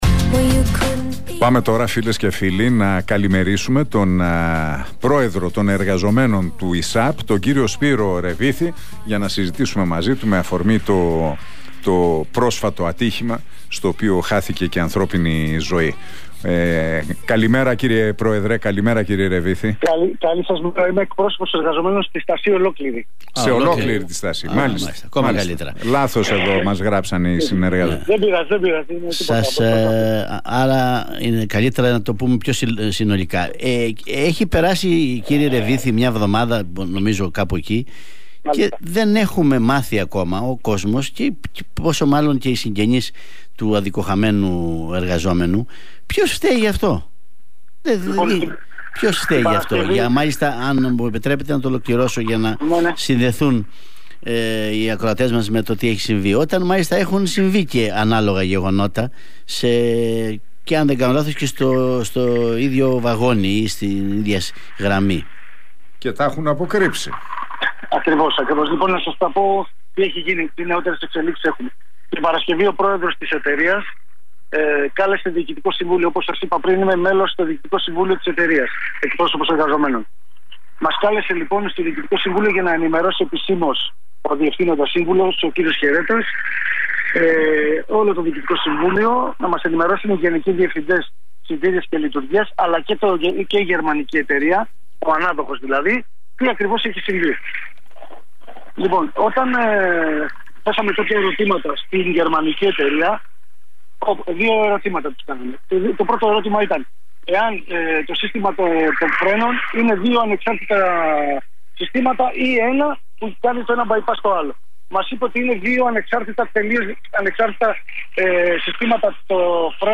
μίλησε στον Realfm 97,8 για τις αποκαλύψεις μετά το δυστύχημα στον ΗΣΑΠ.